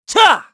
Siegfried-Vox_Attack2_kr.wav